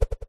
slider.mp3